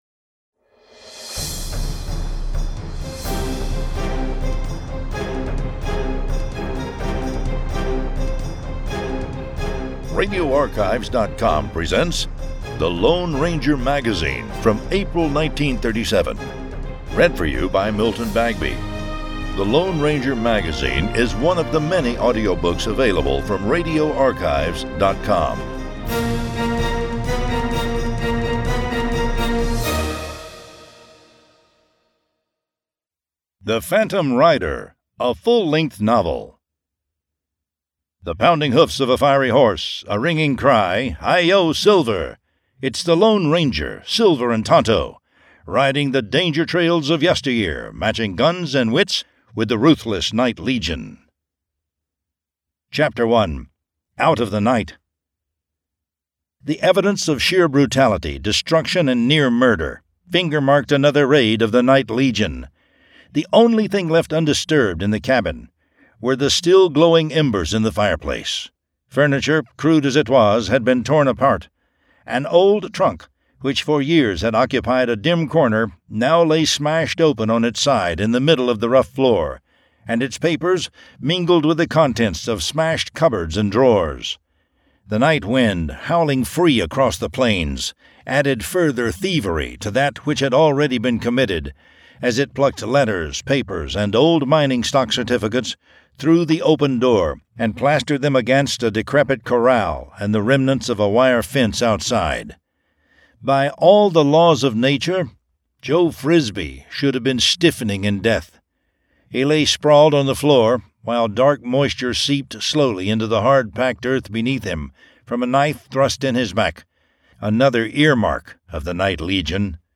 The Lone Ranger Magazine #1 Audiobook